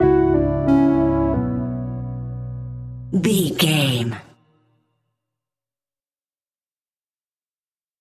Aeolian/Minor
ominous
dark
haunting
eerie
synthesiser
drums
strings
horror music